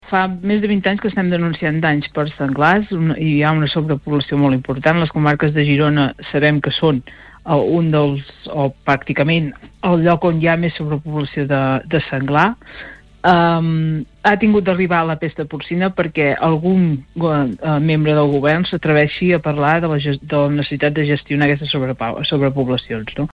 En una entrevista al Supermatí